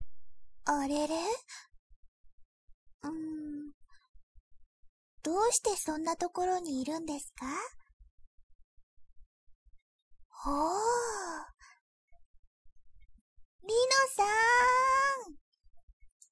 １６歳／女
ゆっくりのんびり、マイペースな少女。
■　Voice　■